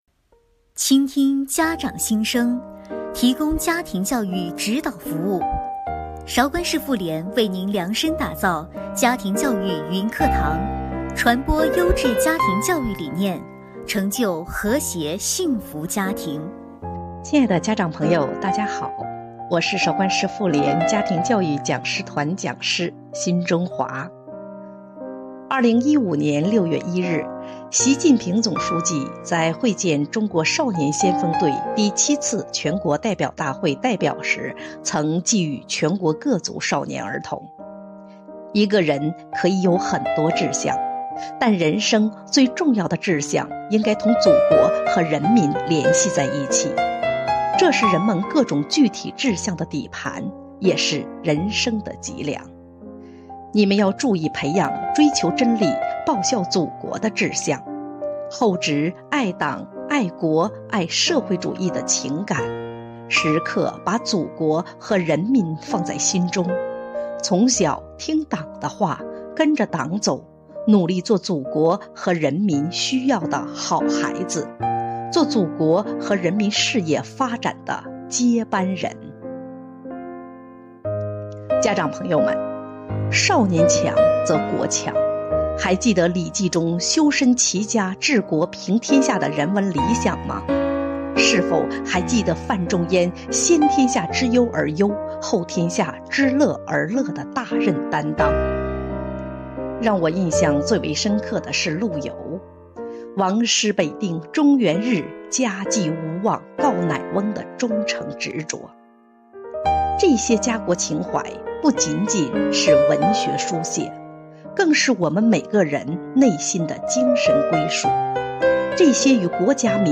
主讲人